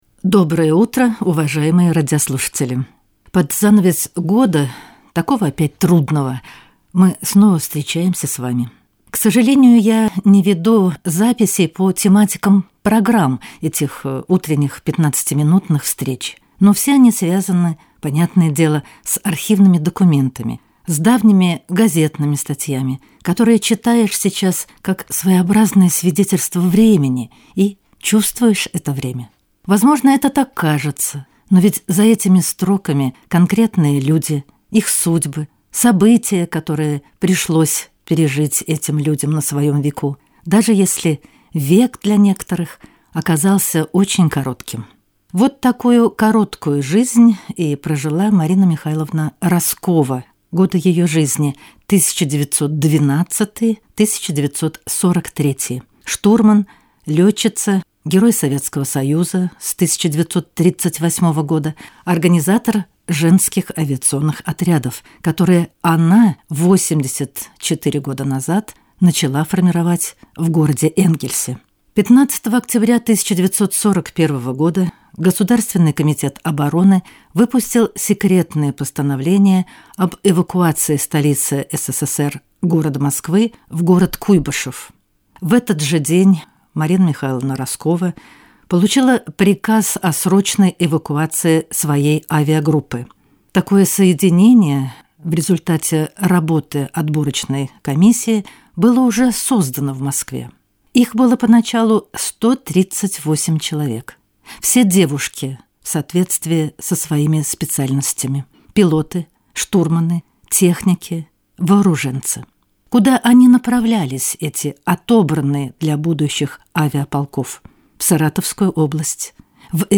04 июля в утренней программе ГТРК Саратов прозвучал радиосюжет, посвящённый юбилею «Артека». В июне этого года Международному детскому центру «Артек» исполнилось 100 лет.